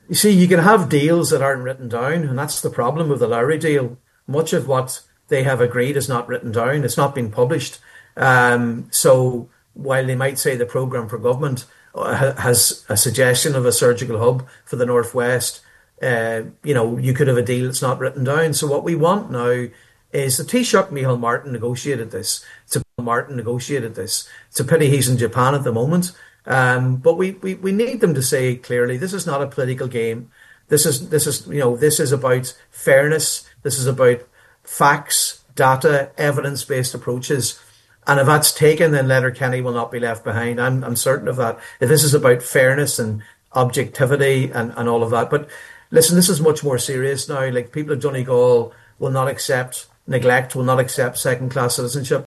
on the Nine ’til Noon Show afterwards, Deputy MacLochlainn says it’s time for the Taoiseach to step in: